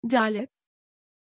Pronunciation of Caleb
pronunciation-caleb-tr.mp3